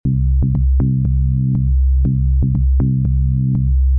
Paso 3: Modulaciones de ancho de banda animadas
Esta vez tiene un carácter más interesante y fluido.